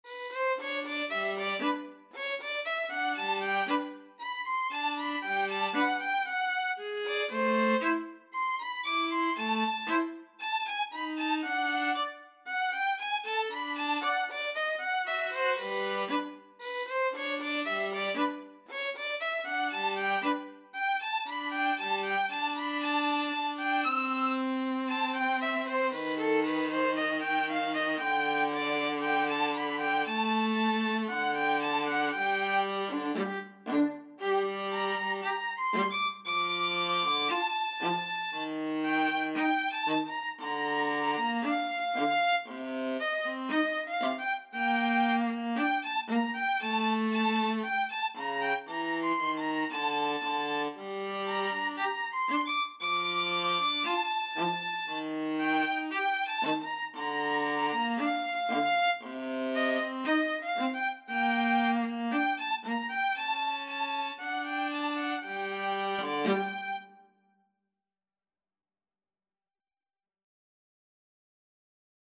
tango song
2/4 (View more 2/4 Music)
World (View more World Violin-Viola Duet Music)